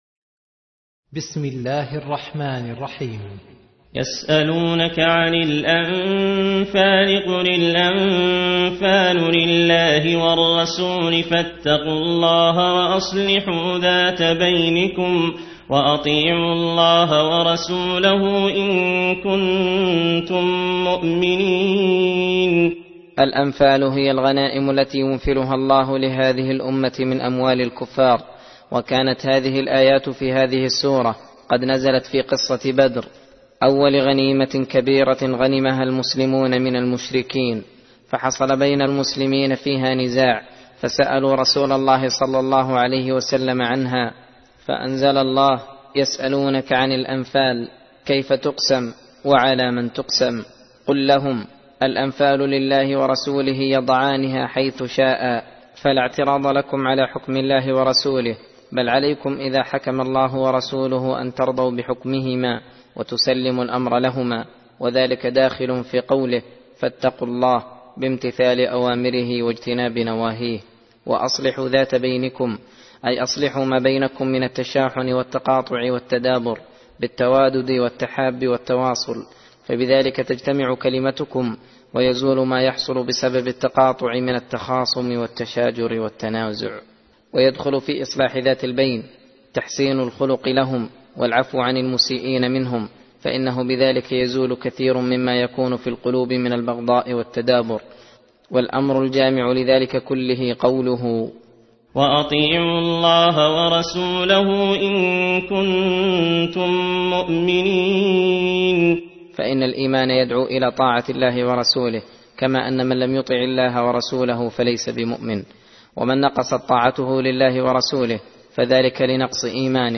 درس (12) : تفسير سورة الأنفال (1-19)